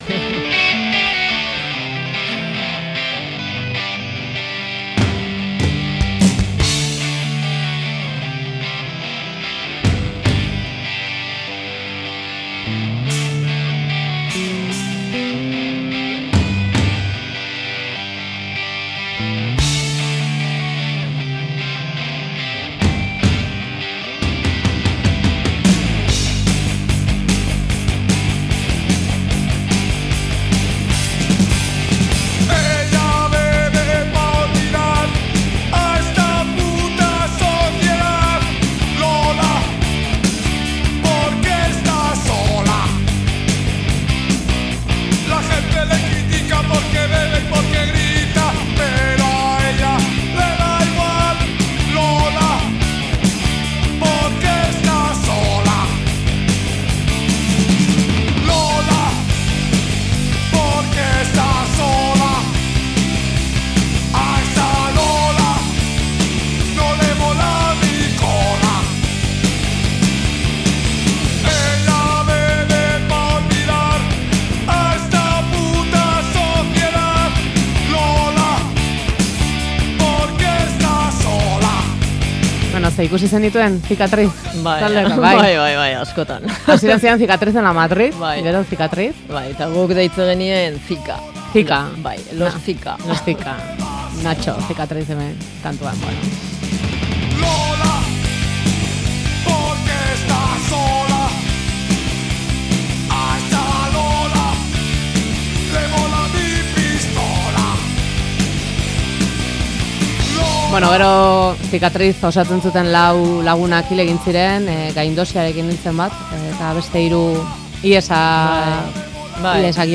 dantzaria Faktorian.